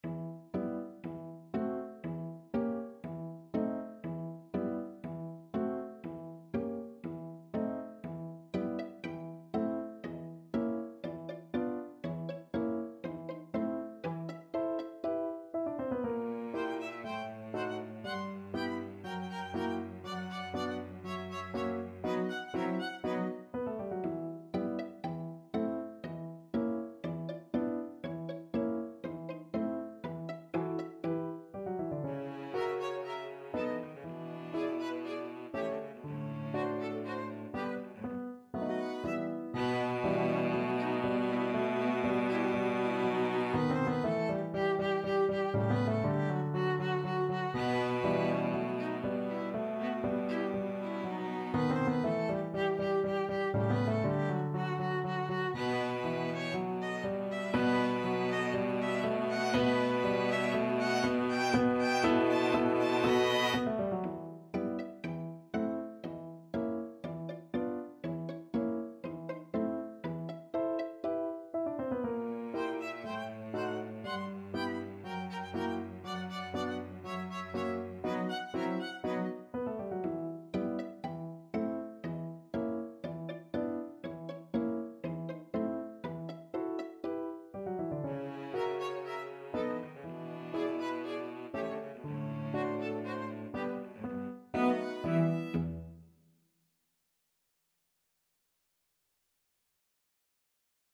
Player 1 - ViolinViolaCelloPiano
E minor (Sounding Pitch) (View more E minor Music for Piano Quartet )
4/4 (View more 4/4 Music)
= 120 Fairly slow and graceful
Piano Quartet  (View more Intermediate Piano Quartet Music)
Classical (View more Classical Piano Quartet Music)